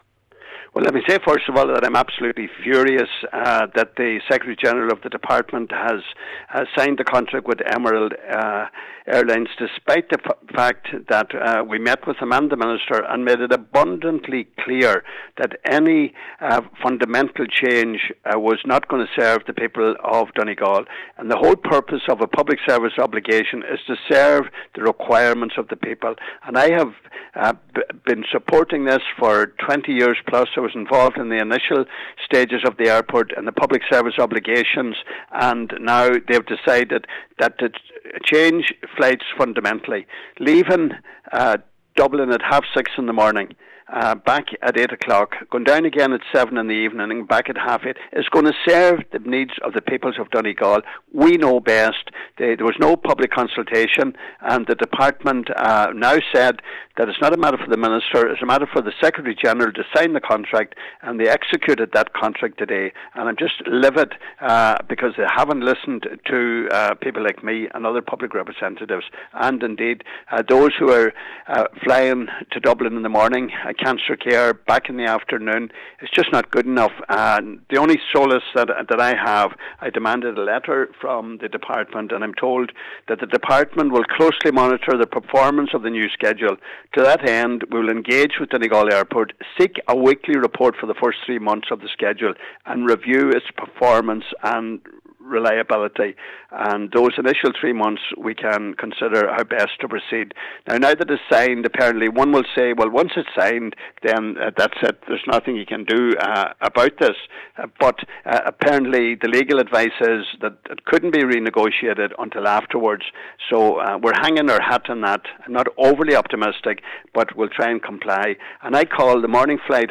Deputy Gallagher says this will reduce flexibility and remove services that are relied upon everyday by cancer patients: